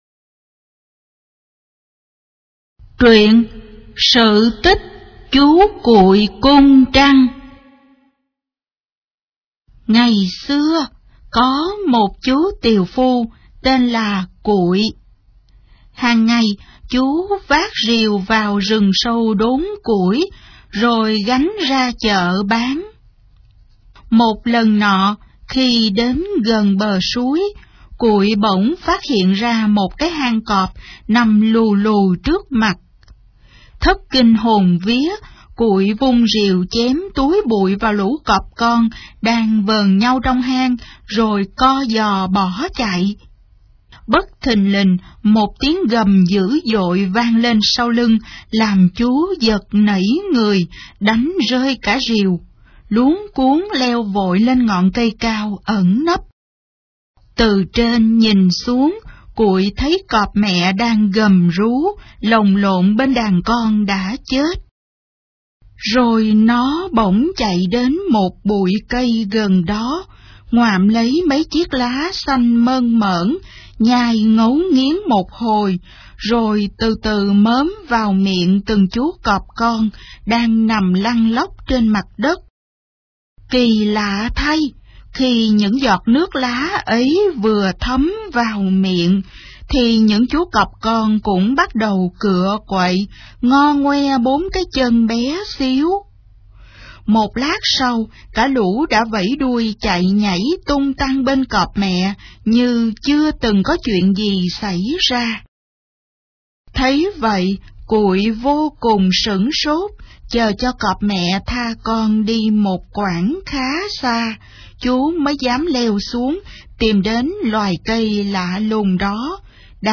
Sách nói | 19_TRUYEN_CO_TICH_VN19